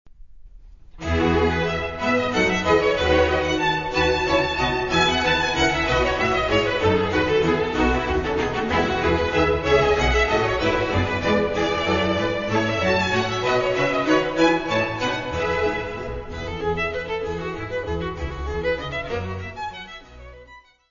: stereo; 12 cm + folheto
Music Category/Genre:  Classical Music
Allegro assai.